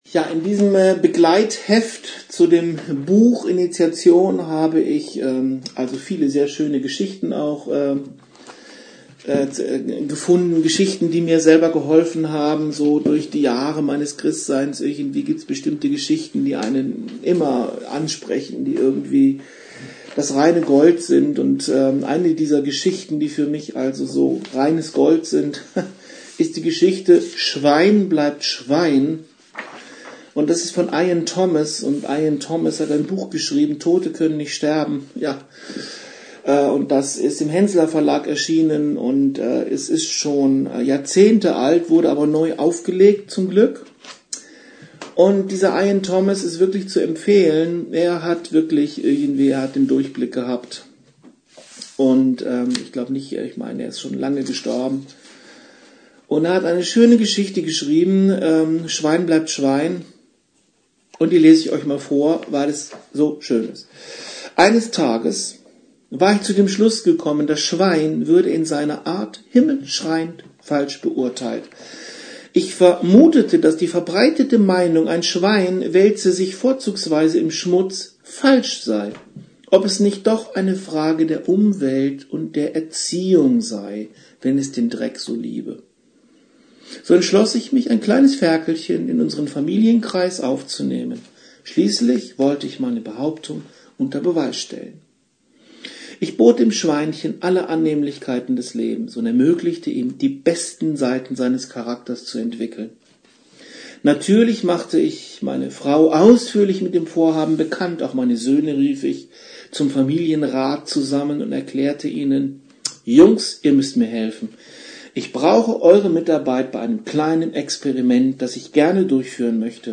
Ich lese aus dem Kursheft eine kleine und witzige Geschichte von